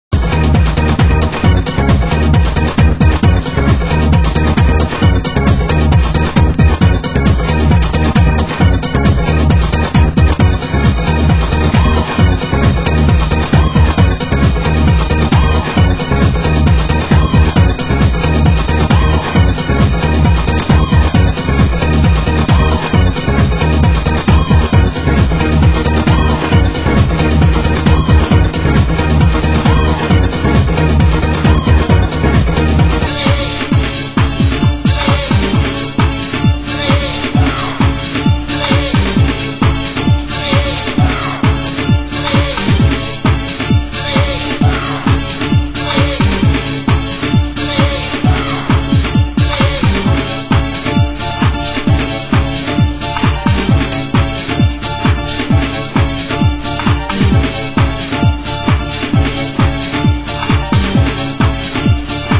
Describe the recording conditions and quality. Format: Vinyl 12 Inch